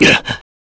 Clause-Vox_Damage_kr_03.wav